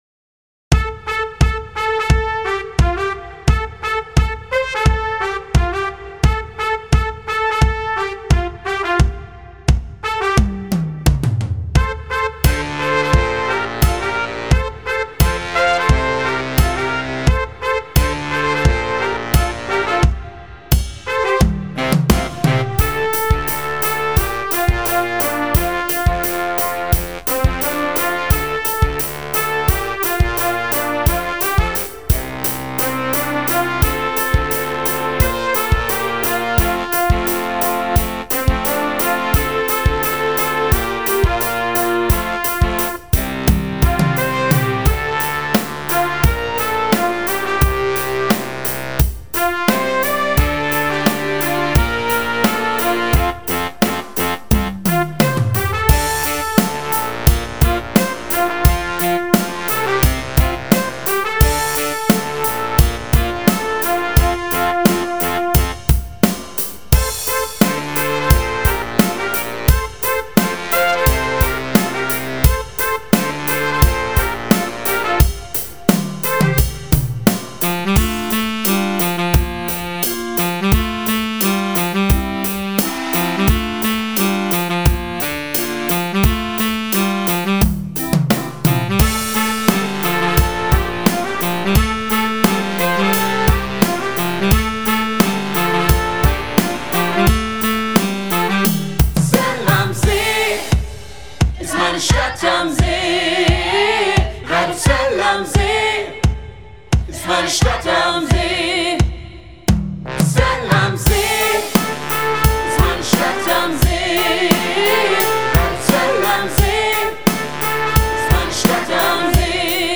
Bläserarrangement kurz und einfach